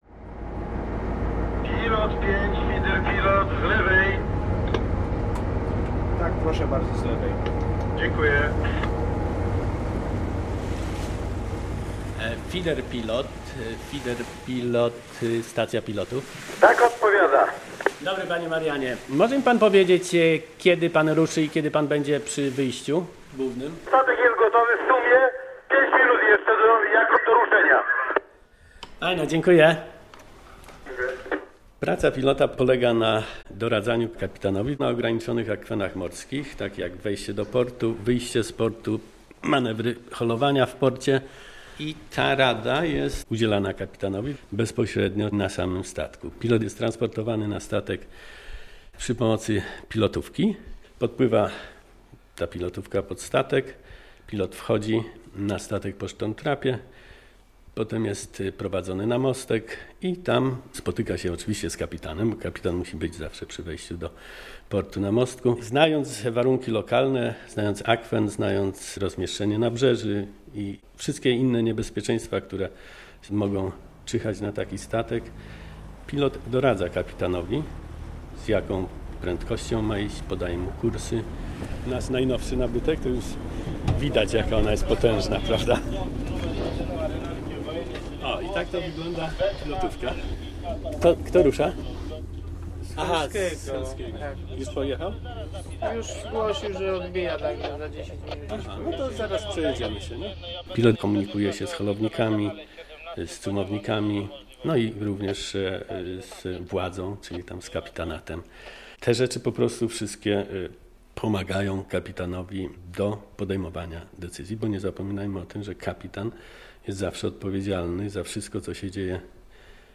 Reportaż o pilotażu morskim
Zapraszamy do wysłuchania reportażu o pilotażu morskim, który został przygotowany przez reporterów I programu Polskiego Radia.